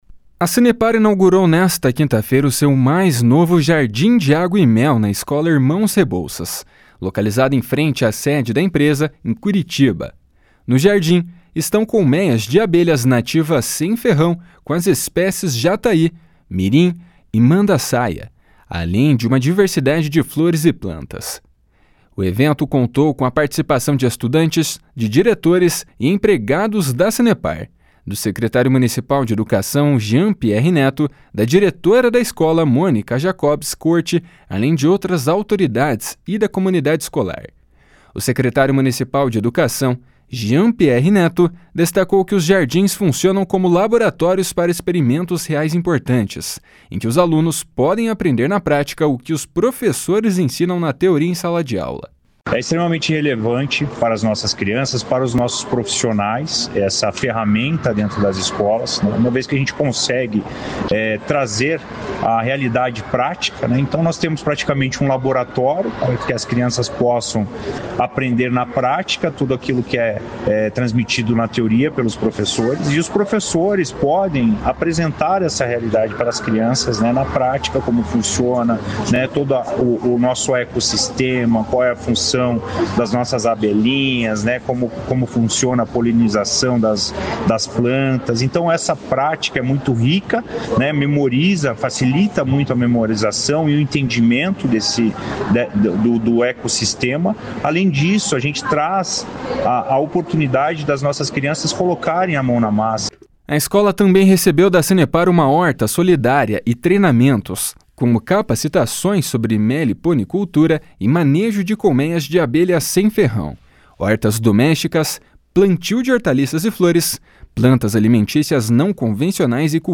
O secretário municipal de Educação, Jean Pierre Neto, destacou que os jardins funcionam como laboratórios para experimentos reais importantes, em que os alunos podem aprender na prática o que os professores ensinam na teoria em sala de aula. // SONORA JEAN PIERRE //